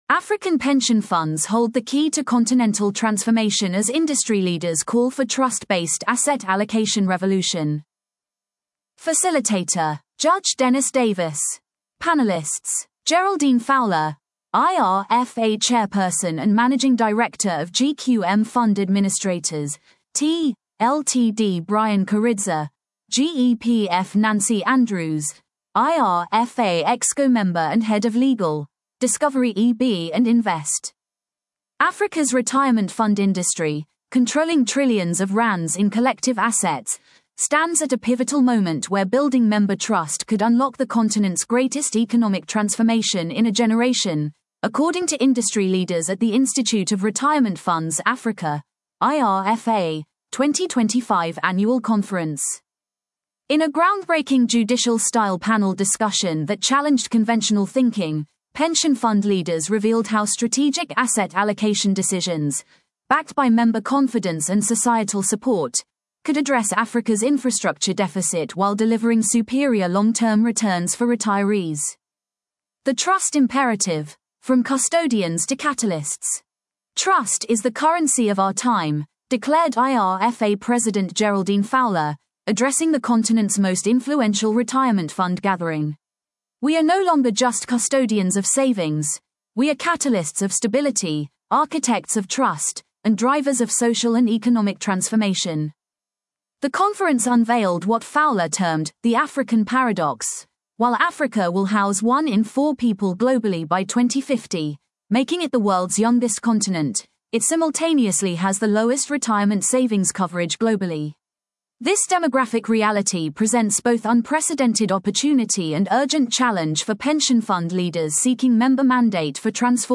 Facilitator: Judge Dennis Davis
In a groundbreaking judicial-style panel discussion that challenged conventional thinking, pension fund leaders revealed how strategic asset allocation decisions – backed by member confidence and societal support – could address Africa’s infrastructure deficit while delivering superior long-term returns for retirees.